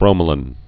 (brōmə-lən, -lān) also bro·me·lin (-lən)